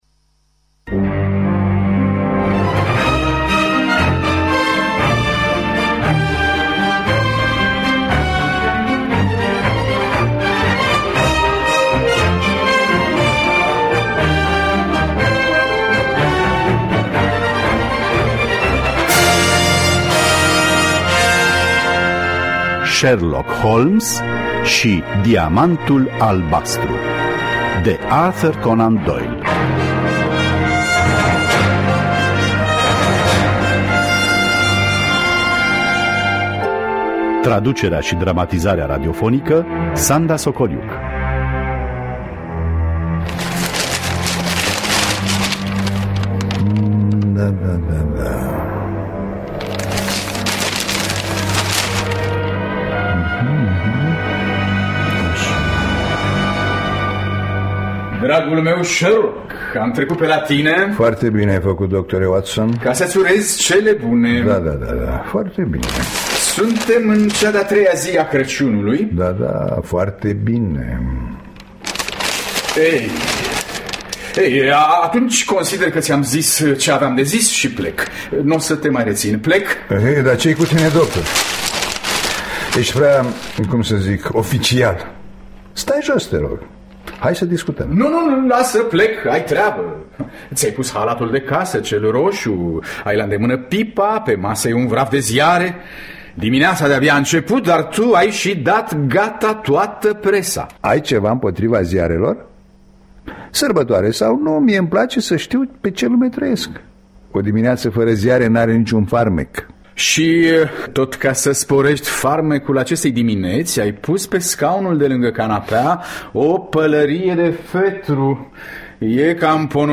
Sherlock Holmes şi diamantul albastru de Arthur Conan Doyle – Teatru Radiofonic Online
Traducerea şi dramatizarea radiofonică